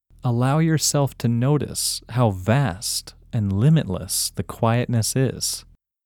OUT – English Male 12